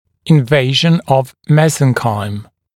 [ɪn’veɪʒn əv ‘mesənkaɪm][ин’вэйжн ов ‘мэзэнкайм]инвазия мезенхимы